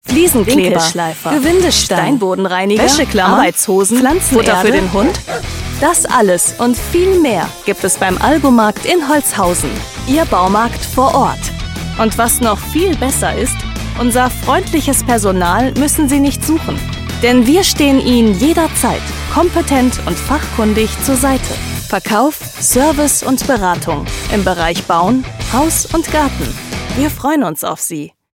Komplexes verständlich erzählt. Professionelle Sprecherin mit eigenem Studio.
Kein Dialekt
Sprechprobe: Werbung (Muttersprache):